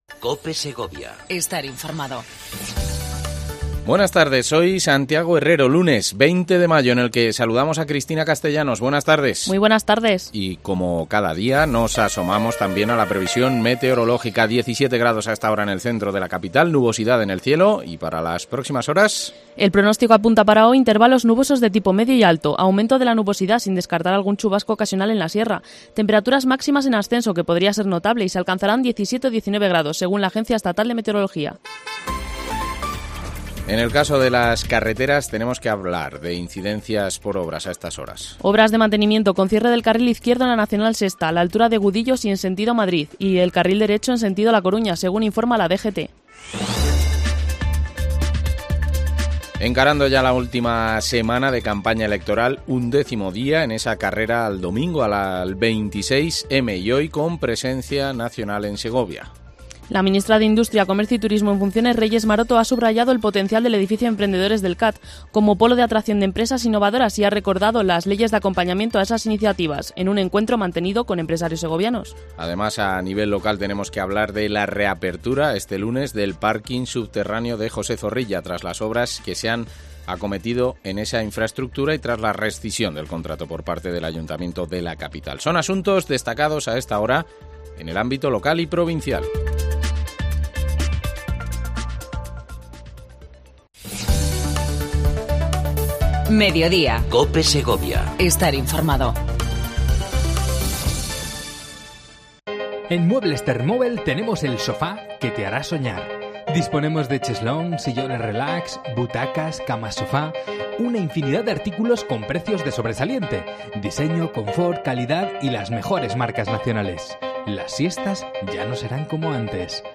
AUDIO: Entrevista a Clara Luquero, actual Alcaldesa de Segovia y candidata socialista a la reelección para la alcaldía de la capital.